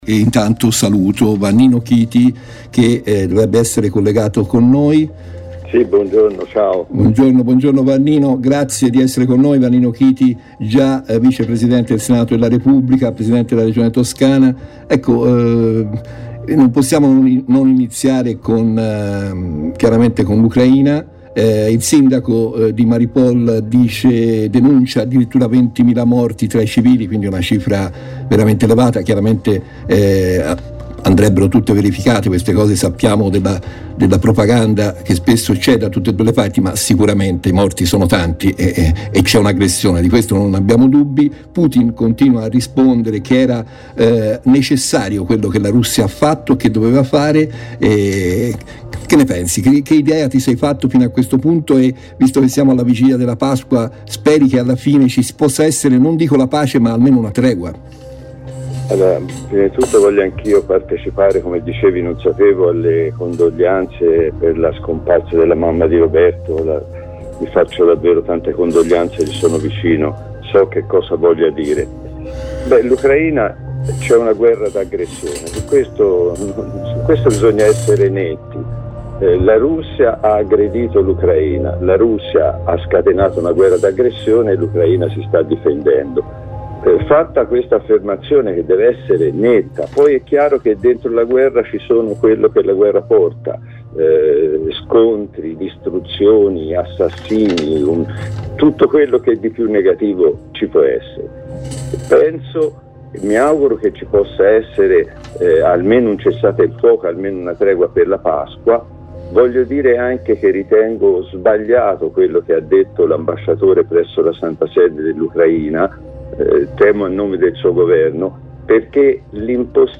L'apertura della "Diretta del Mattino" di mercoledì 13 aprile prende il via con Vannino Chiti , già presidente della Regione Toscana dal 1992 al 2000 e vice presidente del Senato dal 2008 al 2013.